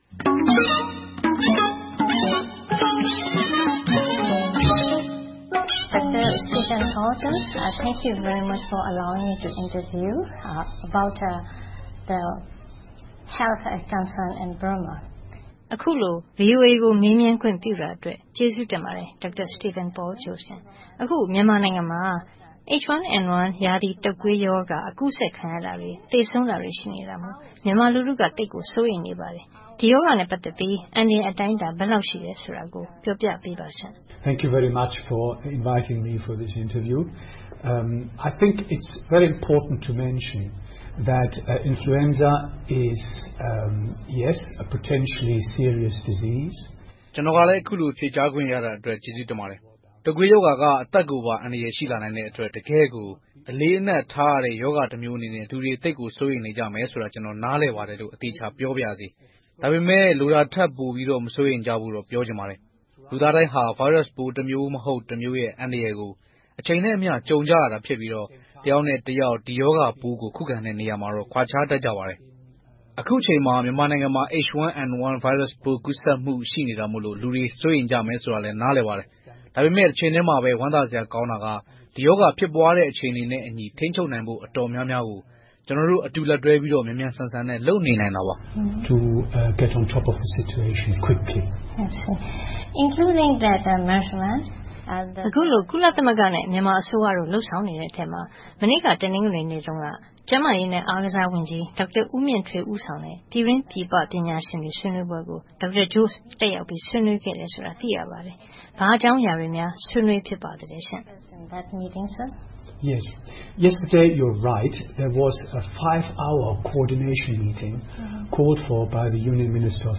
သွားရောက်တွေ့ဆုံမေးမြန်းထားပါတယ်။